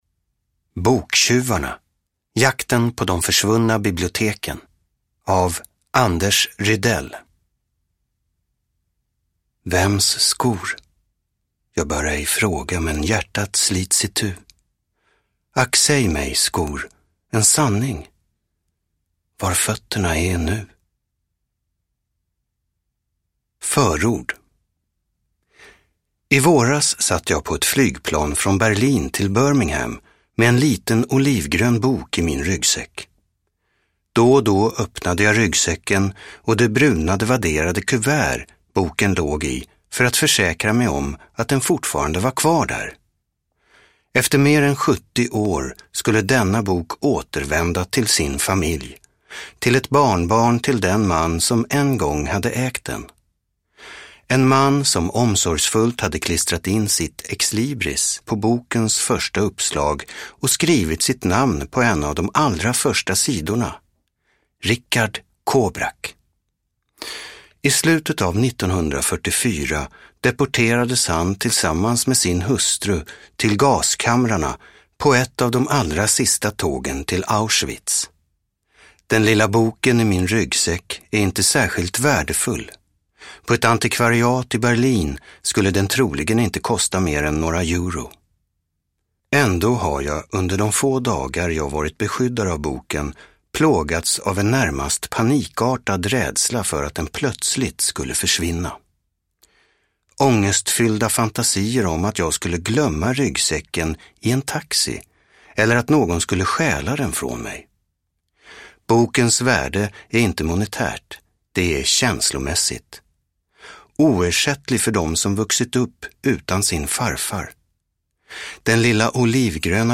Boktjuvarna : jakten på de försvunna biblioteken – Ljudbok – Laddas ner